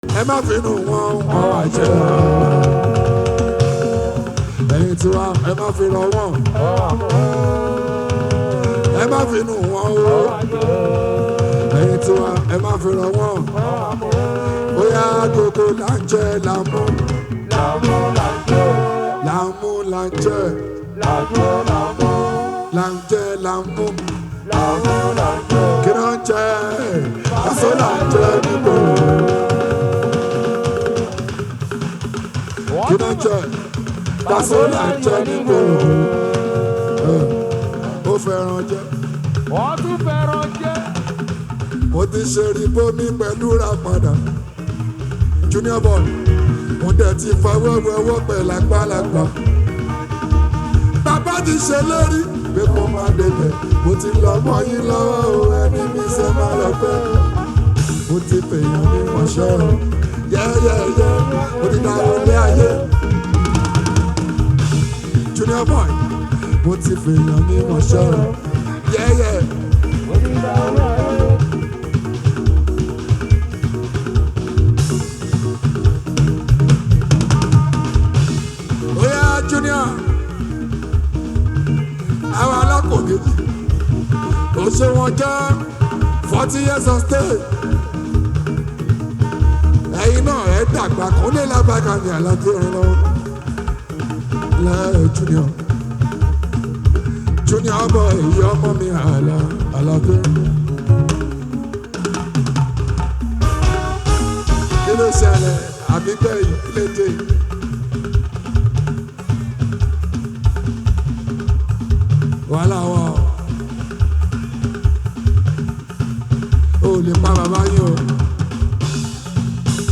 Yoruba Fuji Hit song
Nigerian Yoruba Fuji track
put on your dancing shoes and be ready to dance to the beats